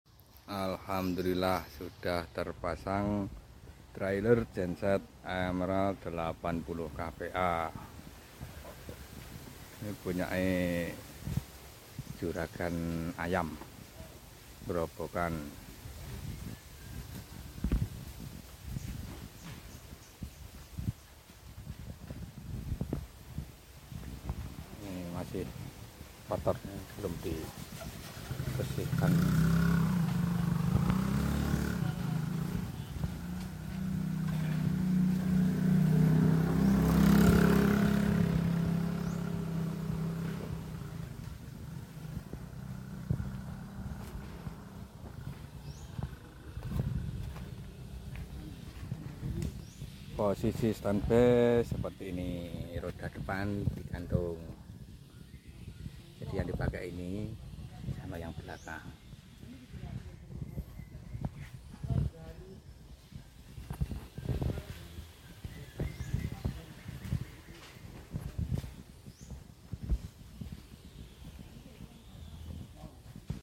terpasang trailer genset emerald 80